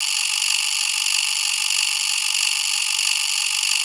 drum-sliderwhistle.wav